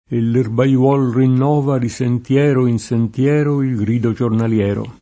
erbaiolo [erbaL0lo] s. m. — oggi lett. erbaiuolo [erbaLU0lo]: E l’erbaiuol rinnova Di sentiero in sentiero Il grido giornaliero [
e ll erbaLU0l rinn0va di SentL$ro in SentL$ro il gr&do JornalL$ro] (Leopardi) — sim. il top. Erbajolo (Cors.)